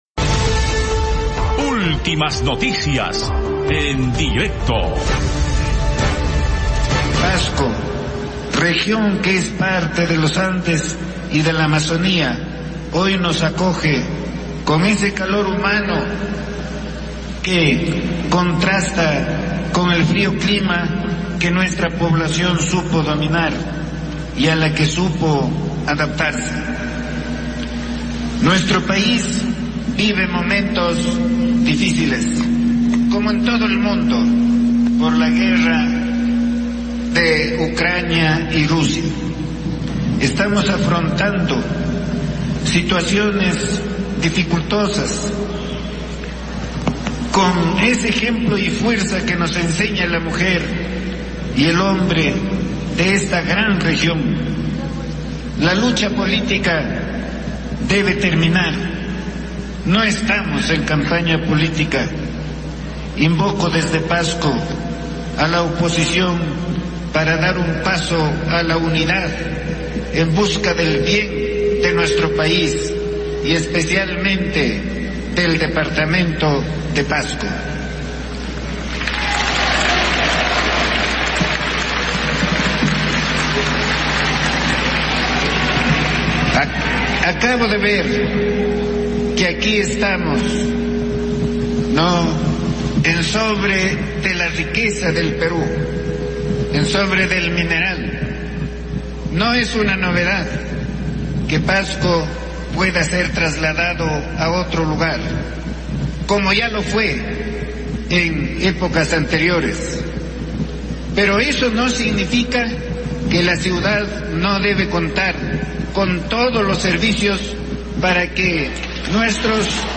Programa Especial
En vivo.